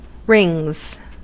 Ääntäminen
Synonyymit still rings circles Ääntäminen US Tuntematon aksentti: IPA : /ˈɹɪŋz/ Haettu sana löytyi näillä lähdekielillä: englanti Käännöksiä ei löytynyt valitulle kohdekielelle.